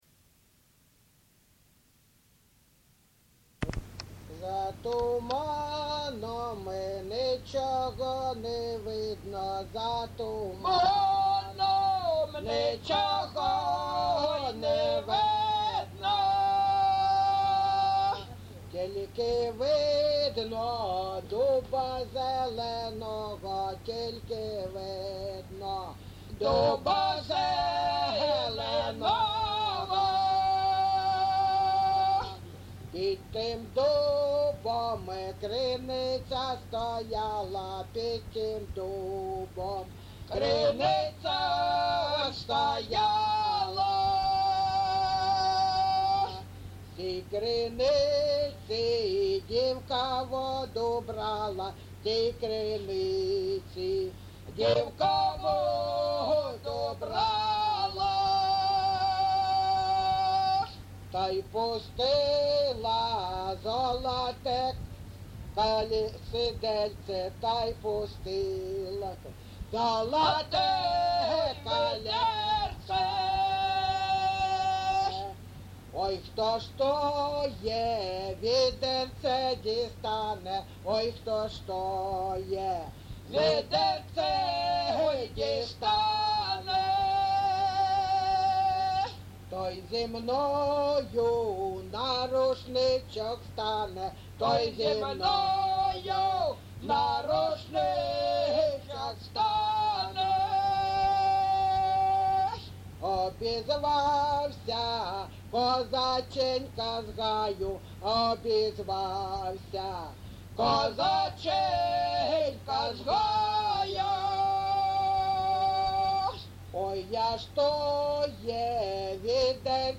ЖанрПісні з особистого та родинного життя
Місце записус. Григорівка, Артемівський (Бахмутський) район, Донецька обл., Україна, Слобожанщина